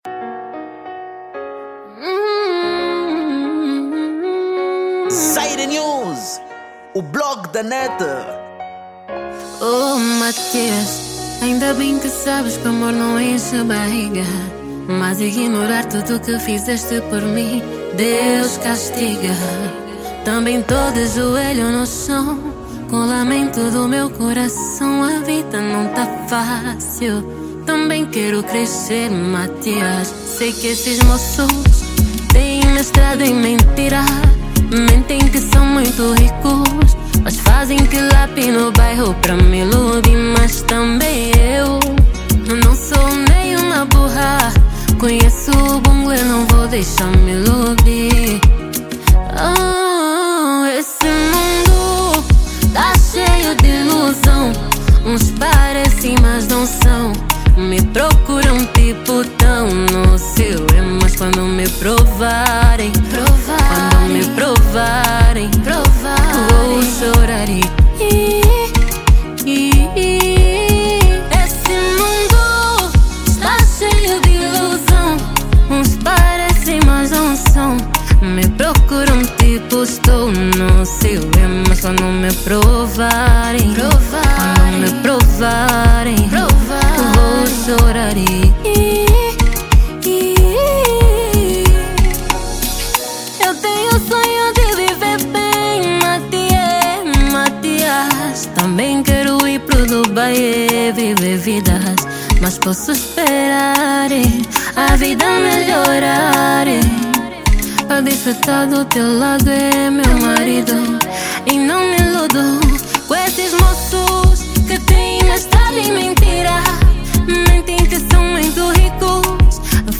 Género : Kizomba Formato